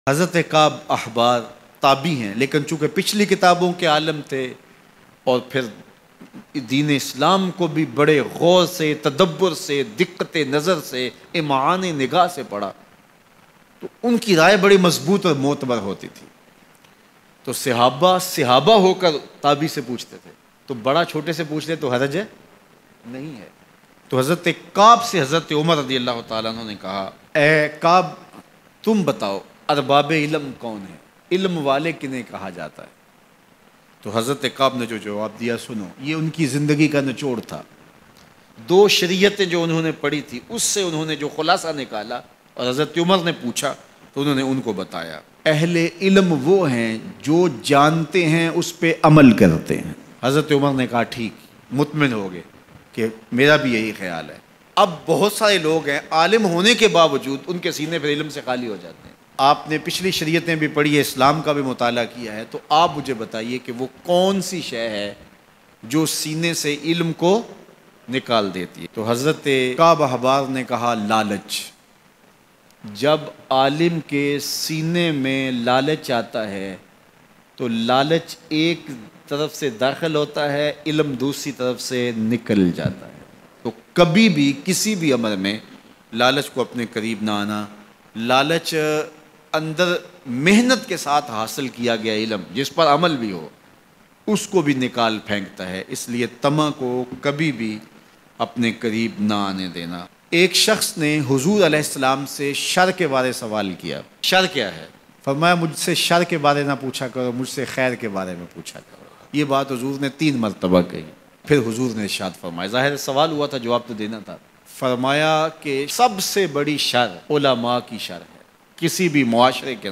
Hazrat UMAR ka 1 Tabaee se Mukalma Bayan